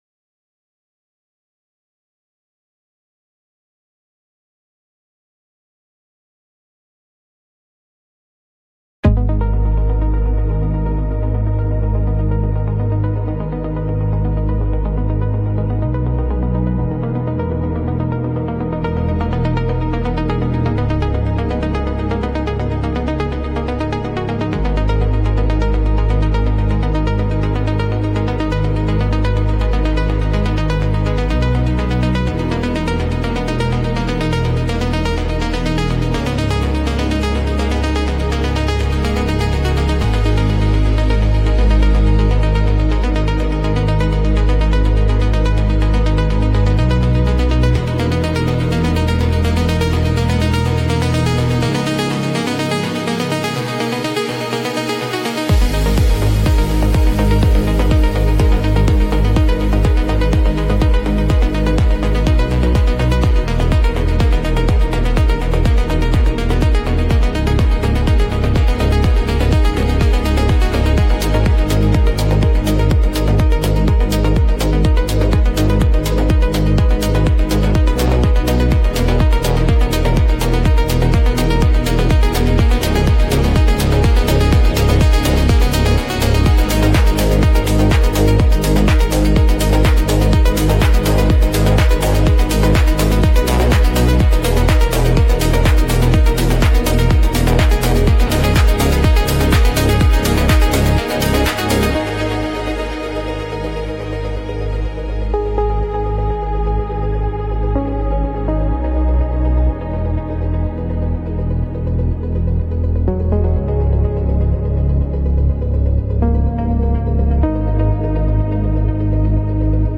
Dark Techno